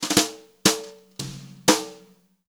120JZFILL1-R.wav